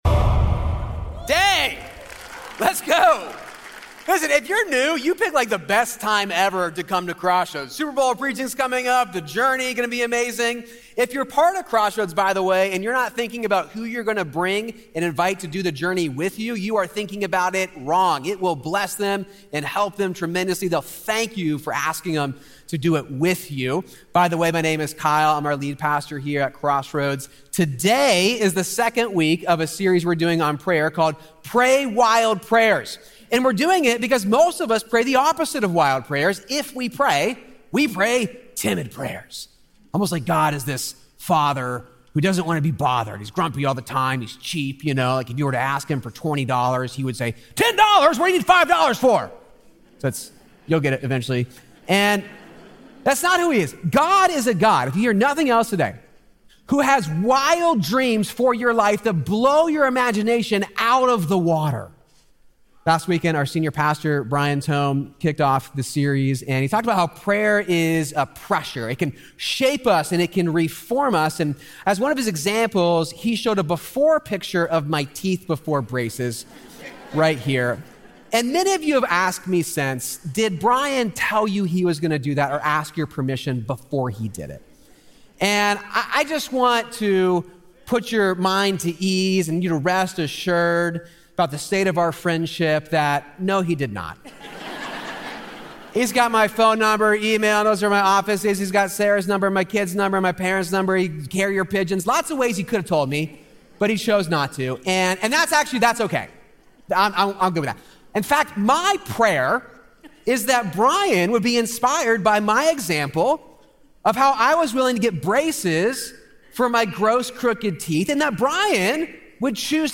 Recorded live at Crossroads in Cincinnati, Ohio.